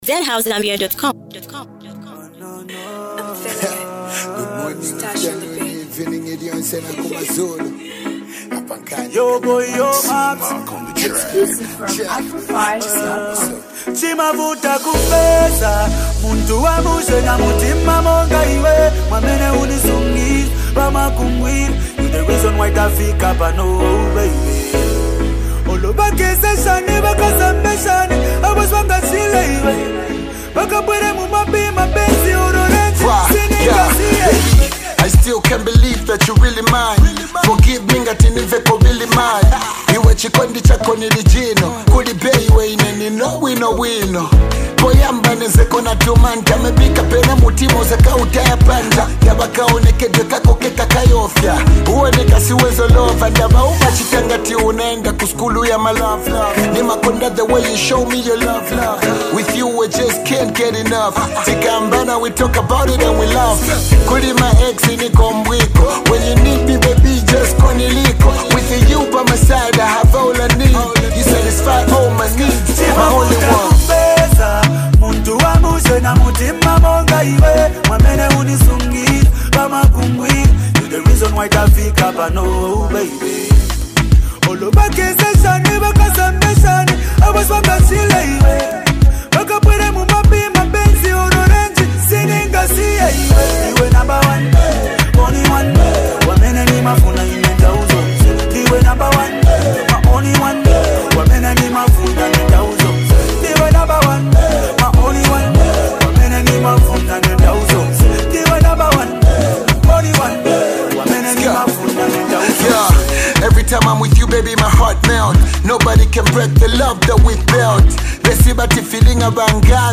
a perfect fusion of rap and melody that celebrates love
soulful chorus
smooth verses